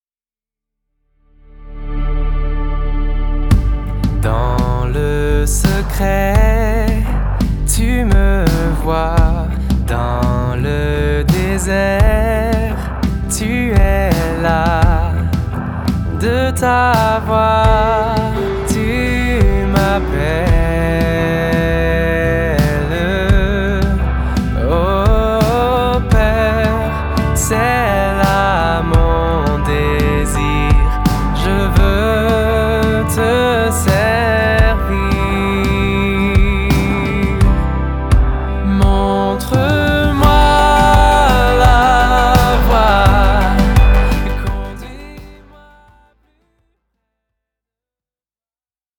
chants de louange aux accents électro